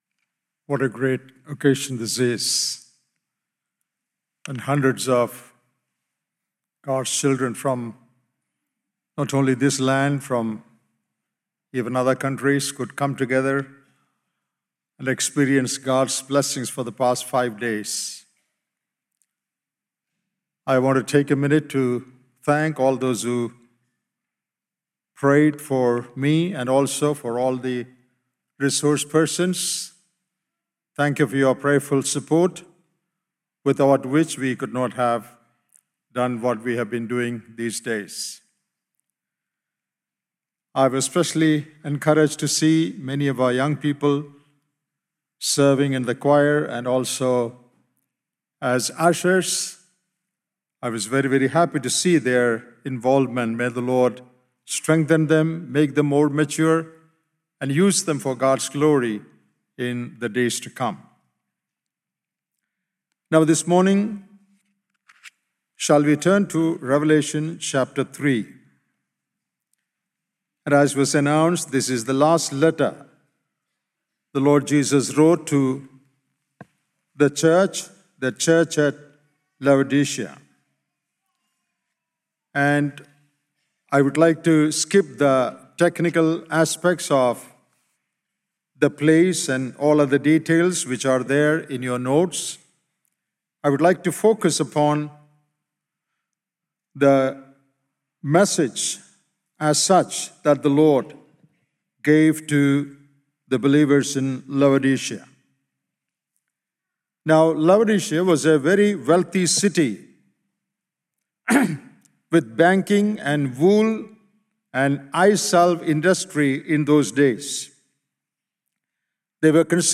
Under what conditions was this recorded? From Series: "IBF Conference 2025"